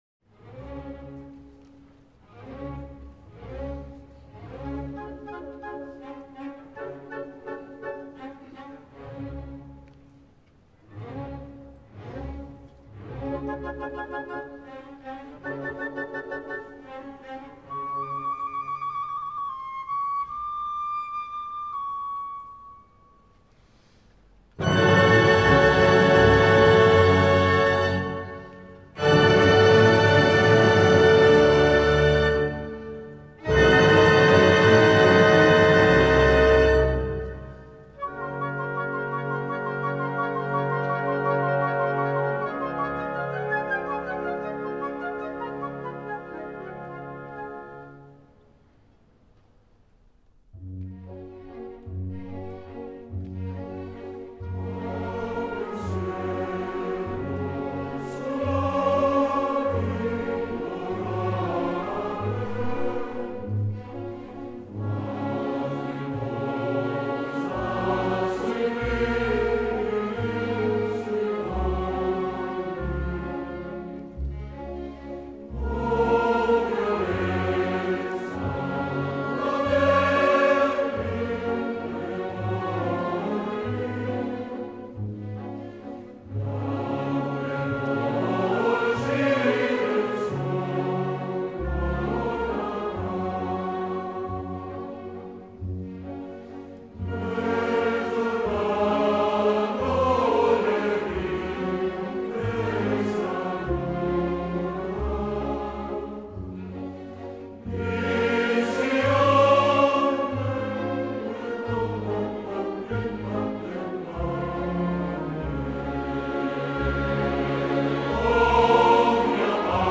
Il CD è stato registrato dall'Orchestra Sinfonica Giovanile del Piemonte dal vivo al concerto per la Festa della Repubblica, presso il teatro Alfieri di Torino (2 giugno 2005).
Coro Femminile Ensemble Vocale Arcadia
Coro e Orchestra Sinfonica Giovanile del Piemonte